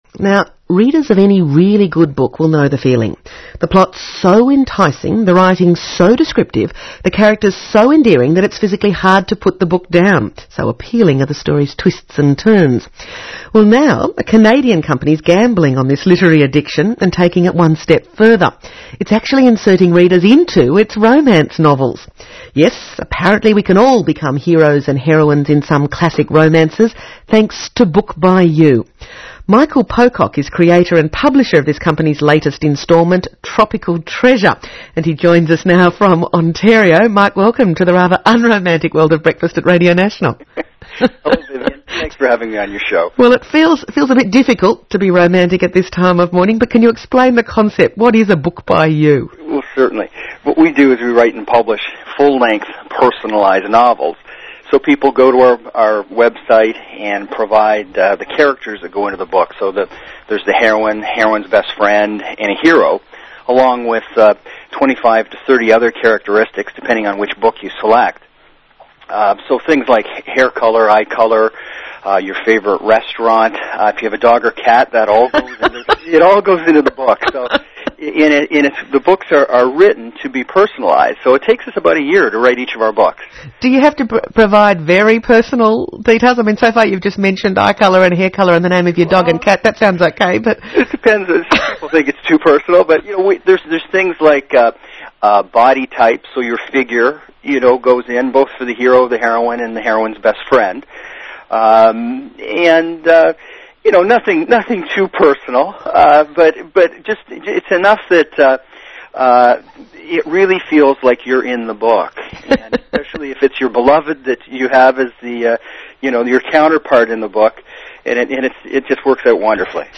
Radio Interview with Australia Broadcasting